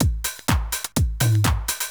DRUMLOOP123_HOUSE_125_X_SC2.wav
1 channel